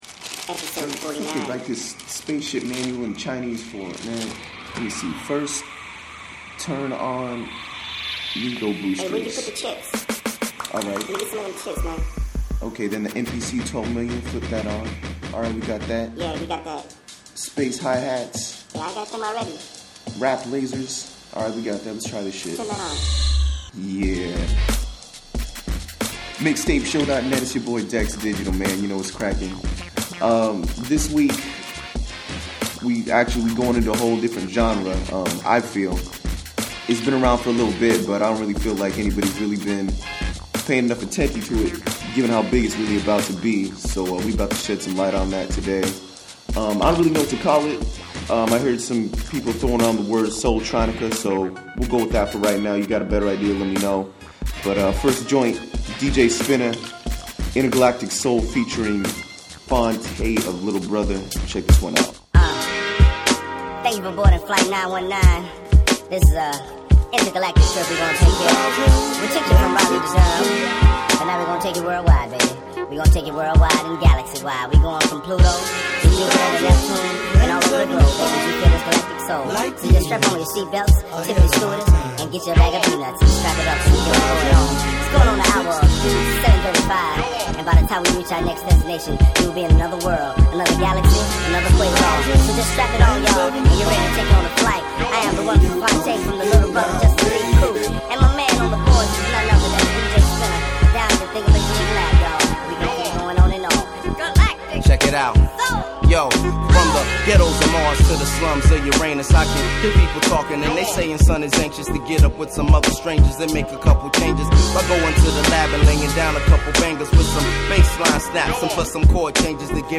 dal gusto soul con accenti di hip-hop e r’n’b.